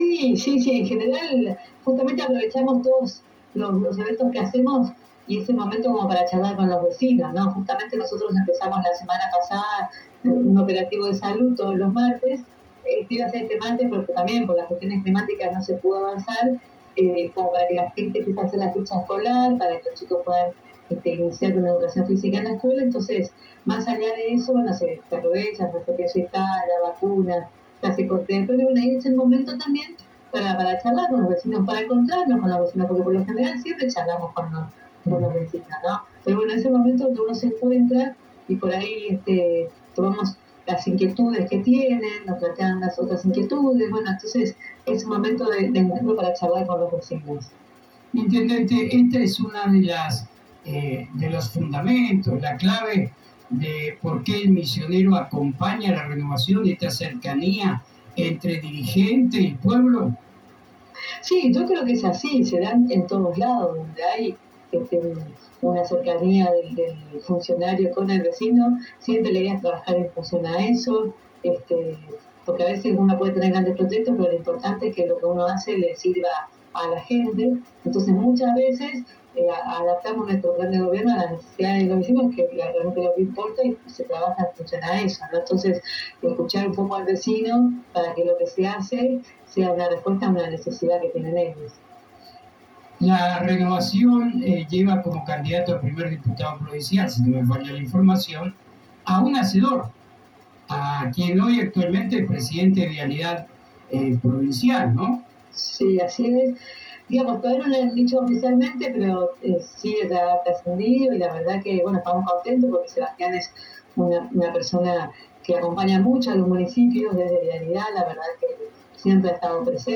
En diálogo exclusivo con la Agencia de Noticias Guacurari (ANG) la Intendente de Apóstoles, María Eugenia Safrán, comentó la situación de las obras públicas que se llevan a cabo en el municipio a su cargo.